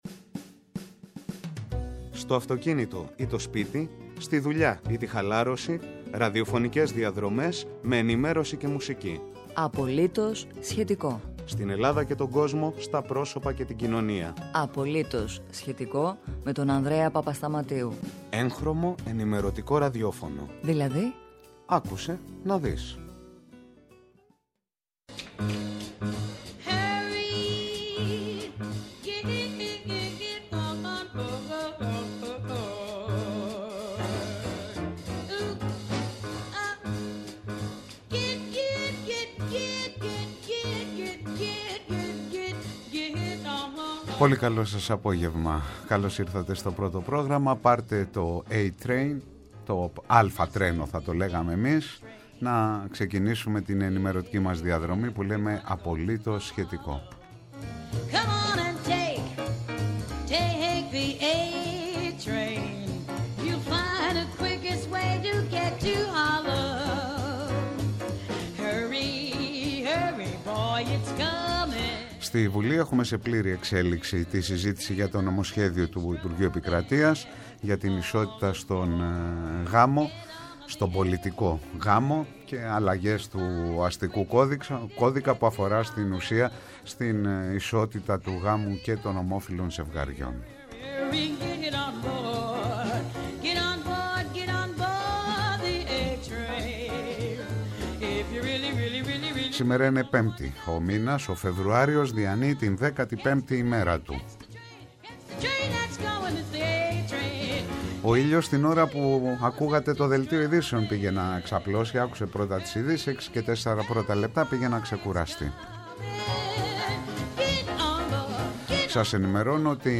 Καλεσμένος απόψε ο Διονύσης Τεμπονέρας μέλος της Πολιτικής Γραμματείας του ΣΥΡΙΖΑ-ΠΣ.